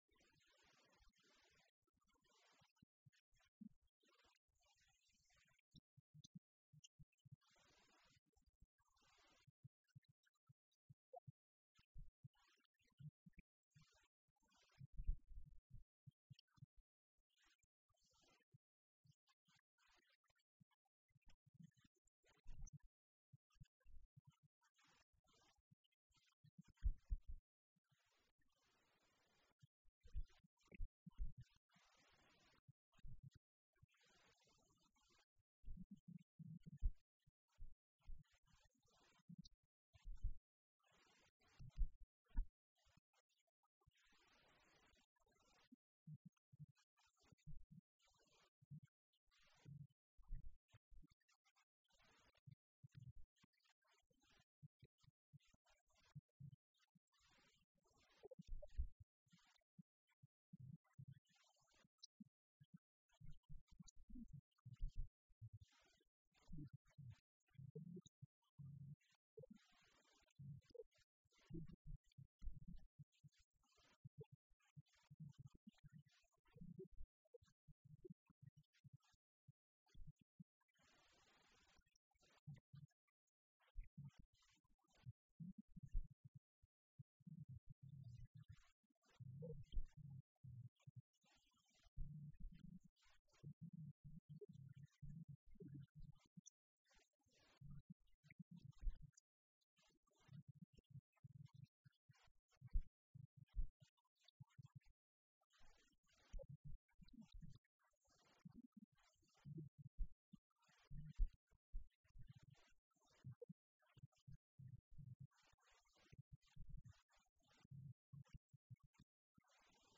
This sermon was given at the Italy 2015 Feast site.